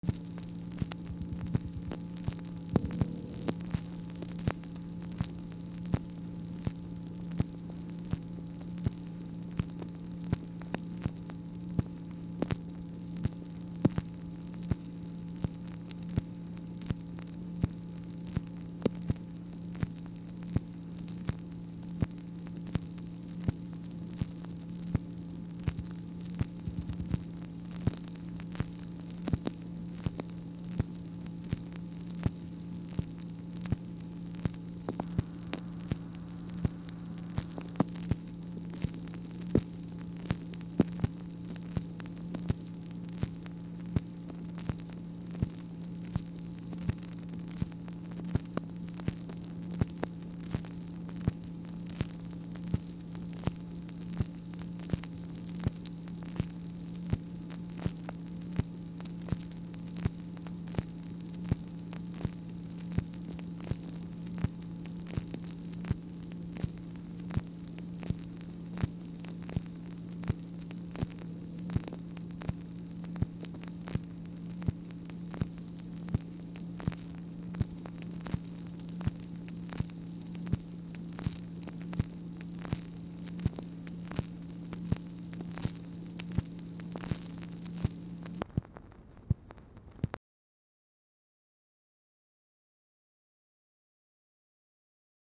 Telephone conversation
MACHINE NOISE
Dictation belt